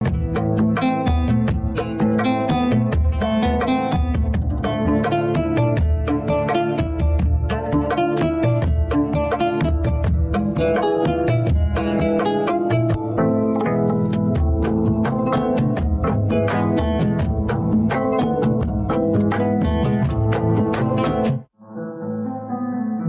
هوش مصنوعی حذف وکال با Vocal remove
نمونه‌ ساخته شده با Vocal remove: